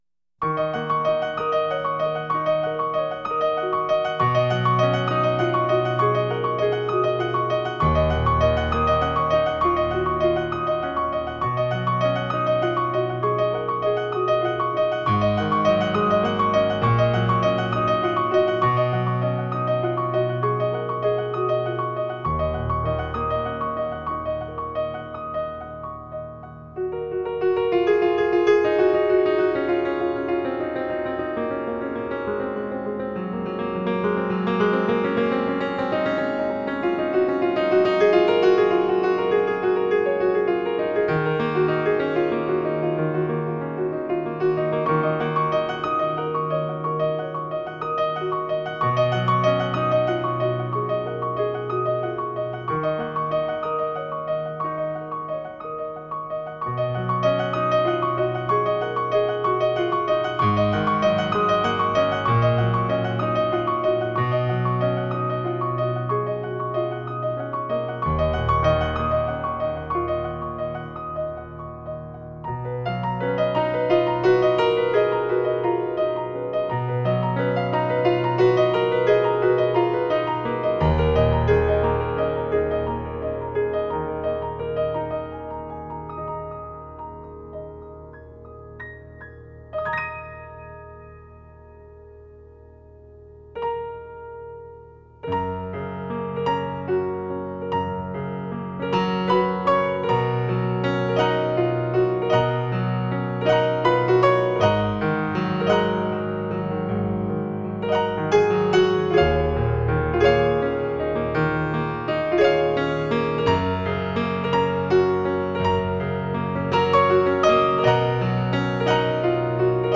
风格;NewAge
清新隽永钢琴乐章
发烧级完美录音
每一首听起来都很类似，只有多听几次才能分出其中的分别。 大部分曲目都是轻柔的旋律加上微妙的变化，适合于静听。